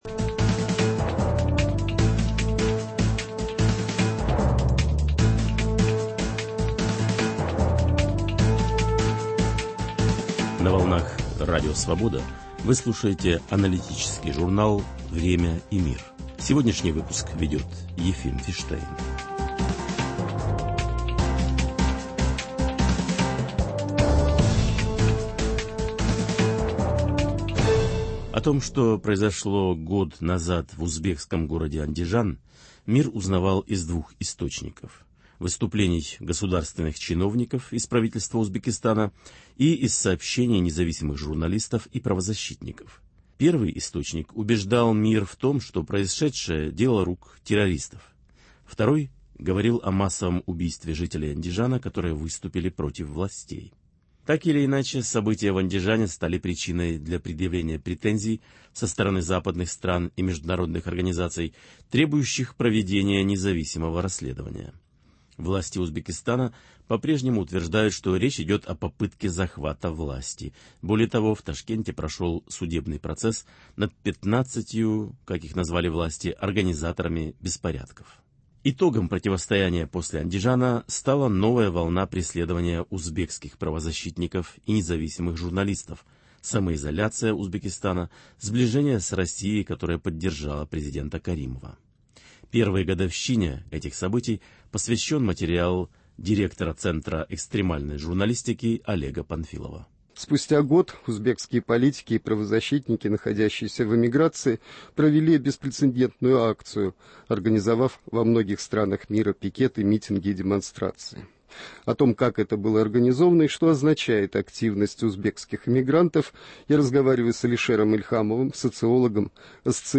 Андижанские события год сустя: беседа с узбекскими правозащитниками